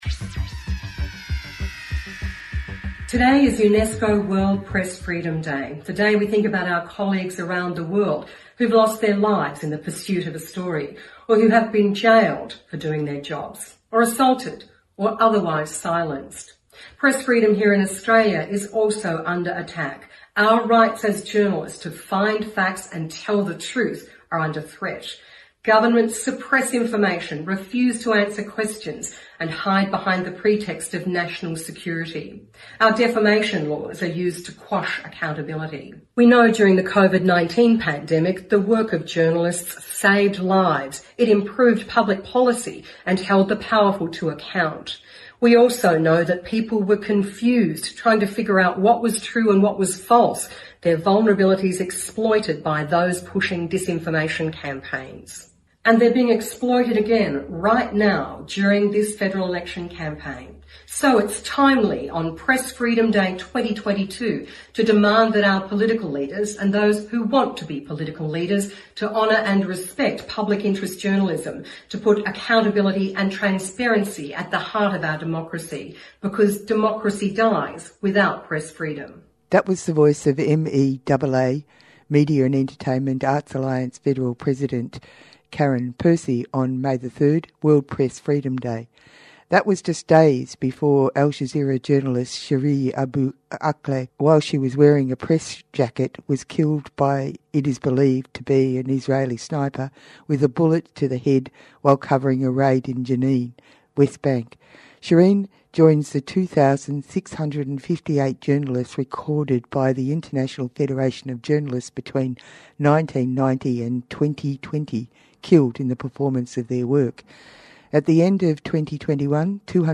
Australia's only national radio show focusing on industrial, social and workplace issues.